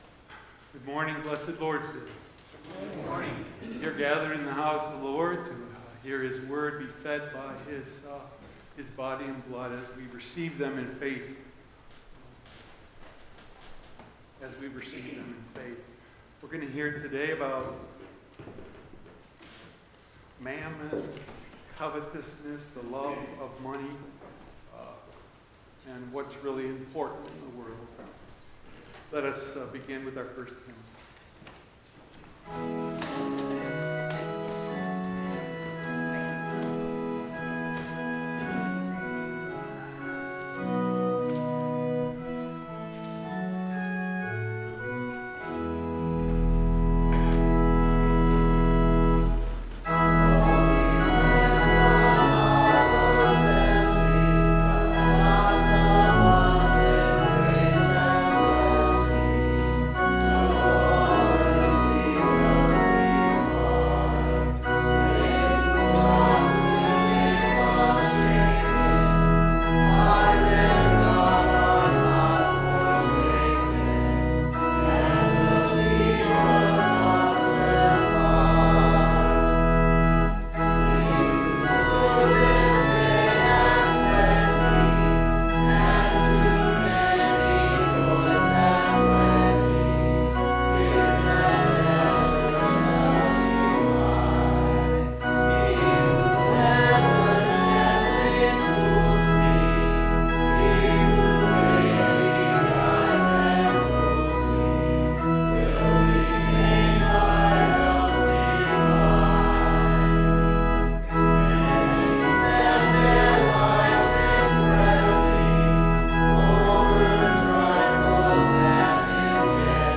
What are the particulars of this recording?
Permission to podcast/stream the music in this service obtained from ONE LICENSE with license A-717990.